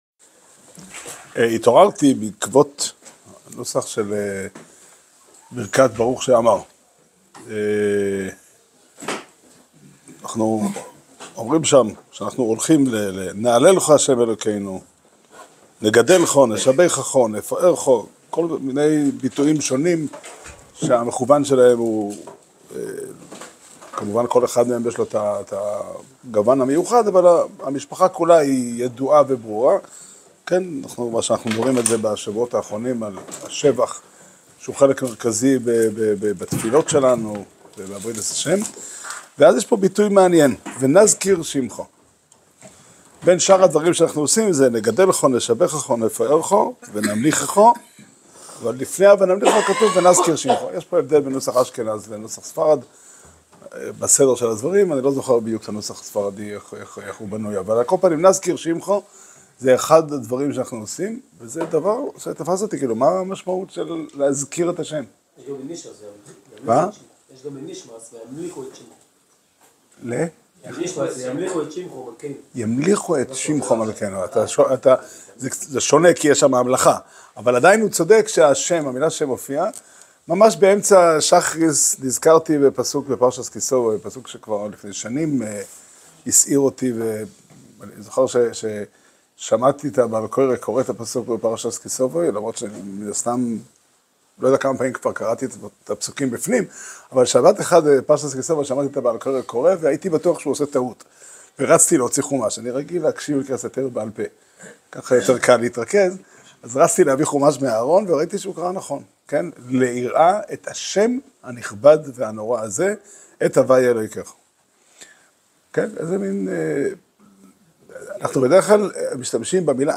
שיעור שנמסר בבית המדרש 'פתחי עולם' בתאריך כ"ז חשוון תשפ"ה